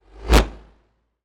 bullet_flyby_deep_04.wav